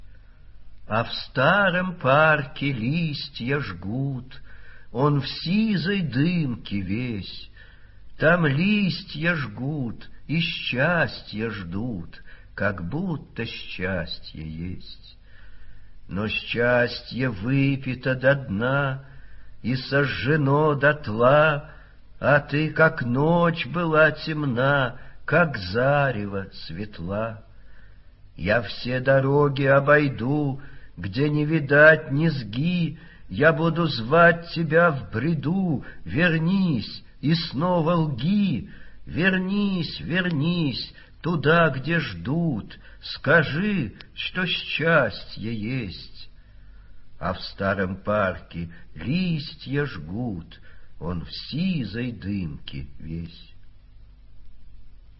vadim-shefner---a-v-starom-parke-listya-jgut...-(chitaet-avtor).mp3